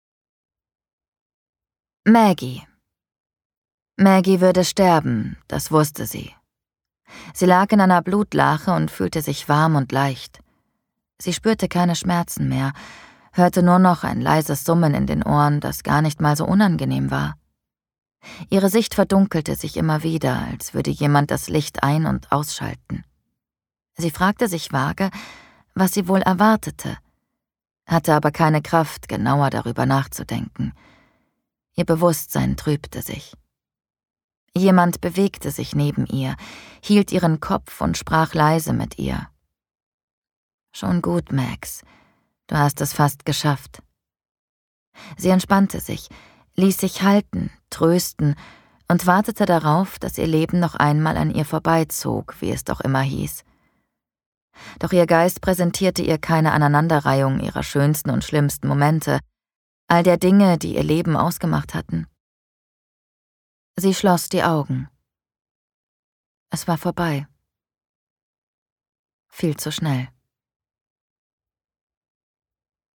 Schlagworte Celebrity • Dating Show • Geheimnis • influencer • Insel • Krimi • love island • Mord • Mörder • Mordfall • Reality TV • Ruhm • Spannung • Thriller • Thriller Hörbuch • Trash TV • weibliche Ermittlerin • White Lotus